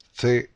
The exception (and only really correct) is Spain, where the correct sound of the syllables formed by Z and any vowel with the right “hiss” is used.